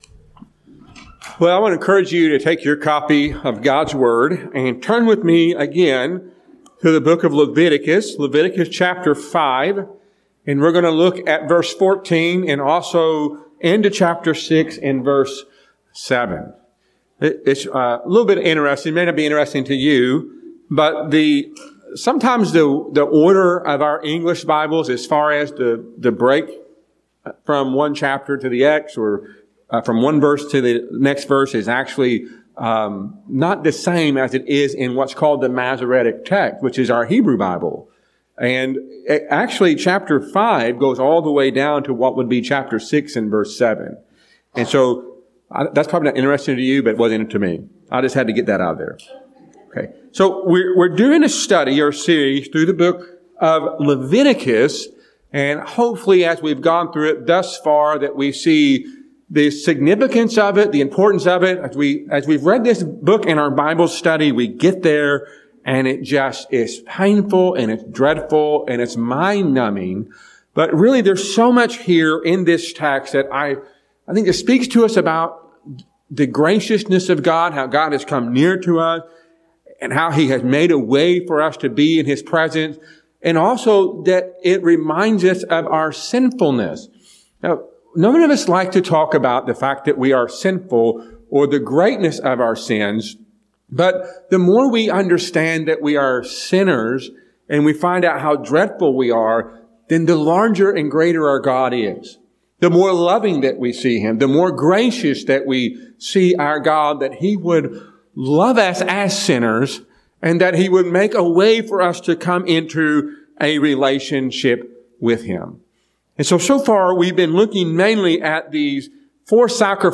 A sermon from Leviticus 5:14-6:7.